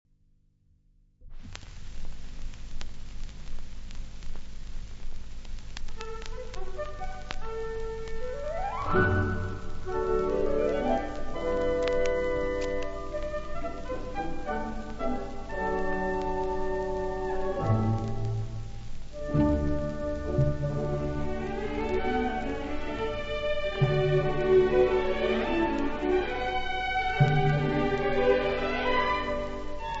• musica sinfonica
• poemi sinfonici